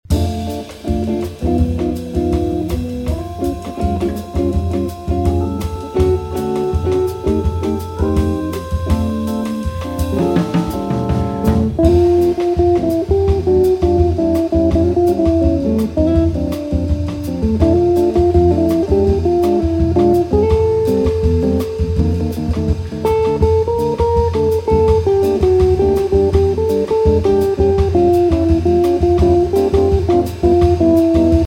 chitarra
batteria
hammond
inciso in California
è eseguito in trio